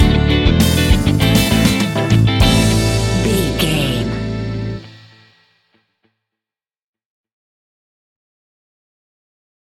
Uplifting
Aeolian/Minor
pop rock
fun
energetic
acoustic guitars
drums
bass guitar
electric guitar
piano
organ